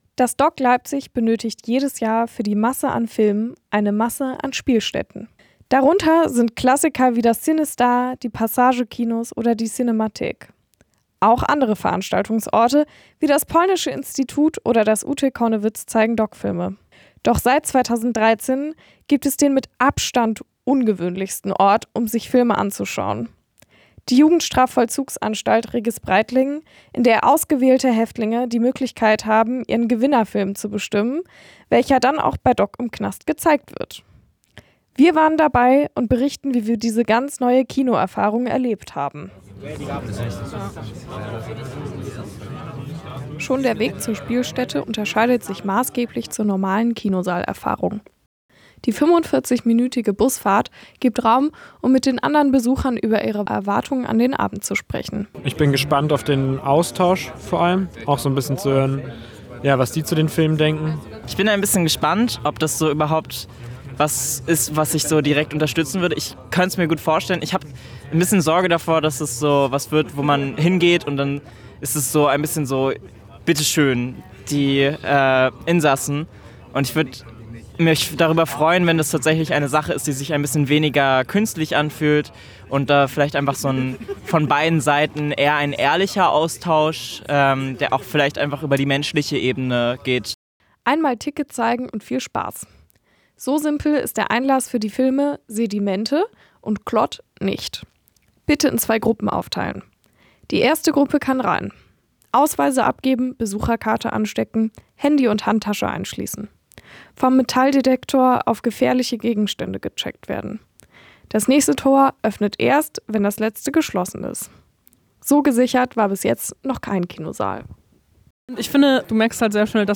Von DOK Spotters 2025Audio, Erfahrungsbericht